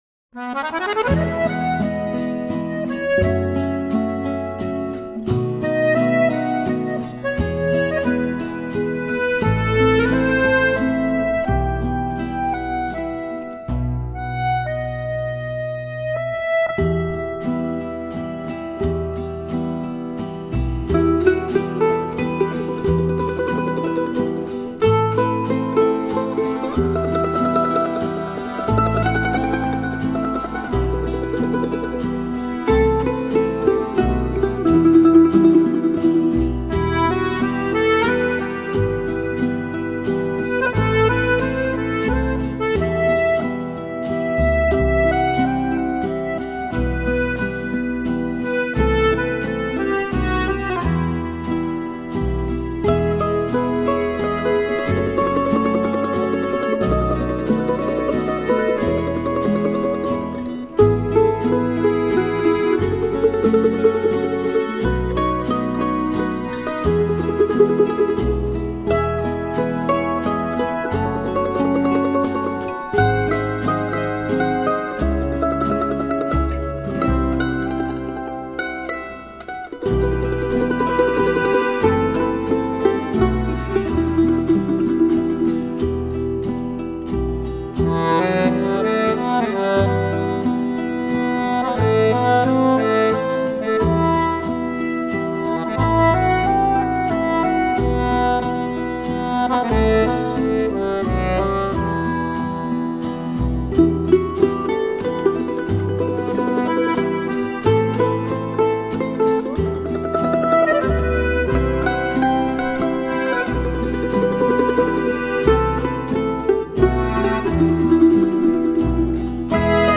Genre: Classical Music, Instrumental, Easy Listening